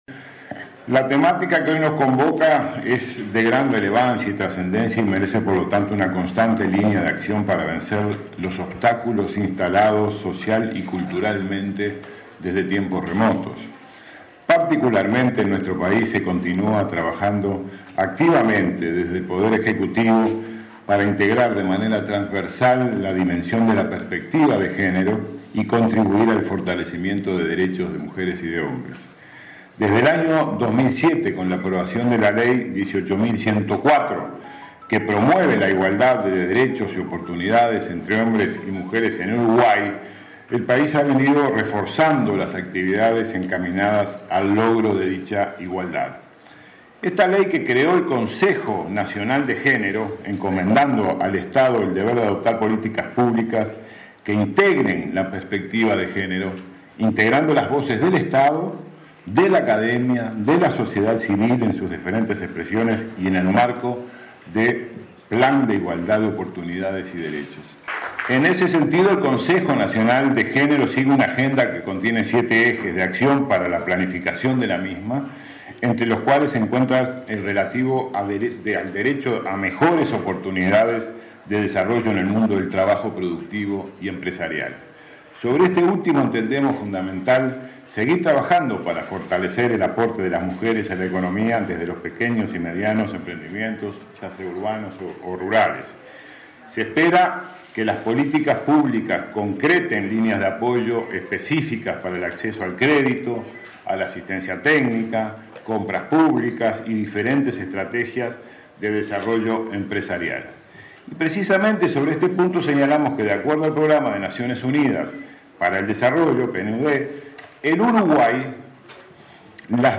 El canciller Nin novoa adelantó que el acuerdo Mercosur-Canadá tendrá un capítulo de género, como en el firmado por Uruguay y Chile. Durante el diálogo regional sobre economía y ciudadanía de las mujeres en América Latina y el Caribe, realizado este martes en Montevideo, habló de la reducción de la brecha salarial que en 2012 se ubicó en 91,2%.